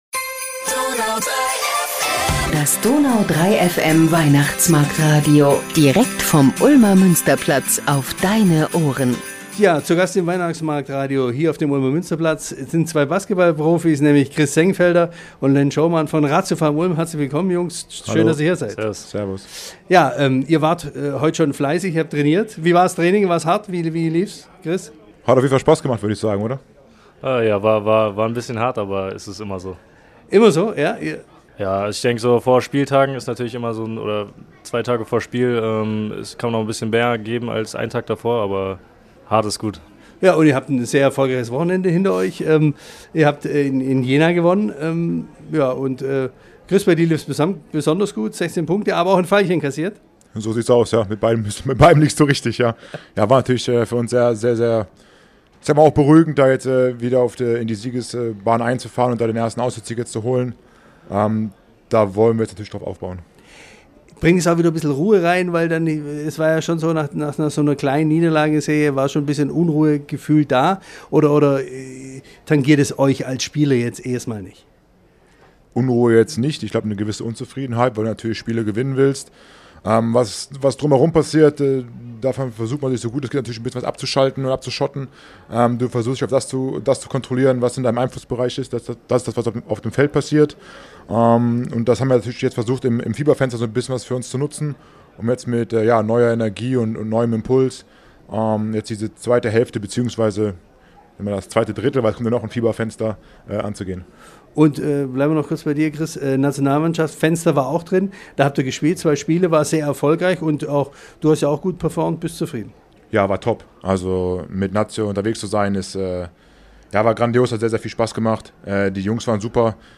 Beschreibung vor 4 Monaten Zwischen Glühwein-Duft und Lichterglanz haben die beiden nicht nur über ihre Weihnachtstraditionen gesprochen, sondern sogar ein Lied angestimmt – so wie es ihr Team jedes Jahr vor dem letzten Heimspiel macht. Warum Weihnachten für die Ulmer Basketballer eine besondere Rolle spielt, welche Erinnerungen sie damit verbinden und wie viel Naschen ein Profi erlaubt, hört ihr im neuen Podcast. Das komplette Interview gibt’s jetzt bei uns.